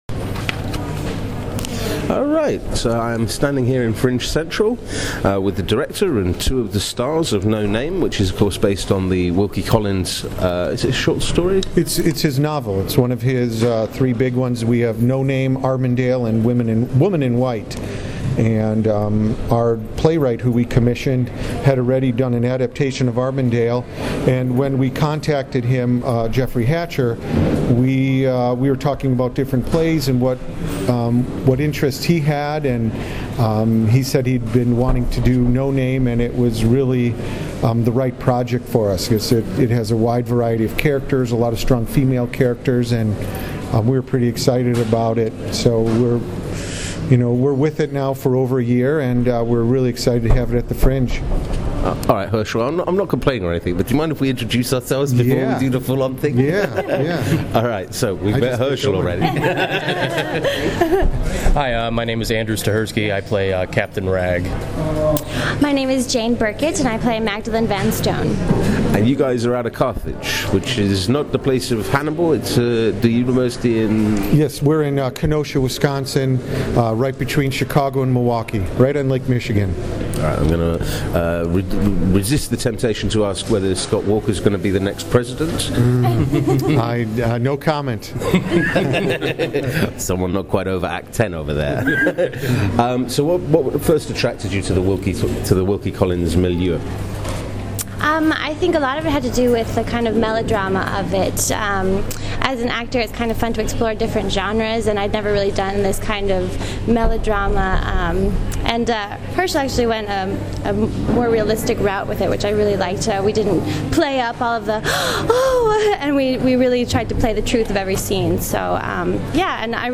Edinburgh Audio 2014
talks to the team behind No Name